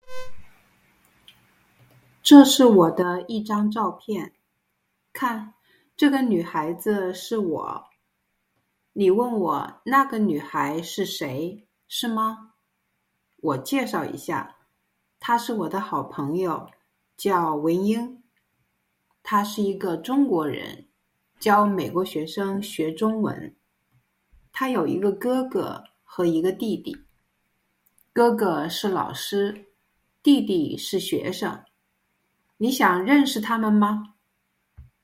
slow speed reading:
L2D1-narrative-reading-slow.mp3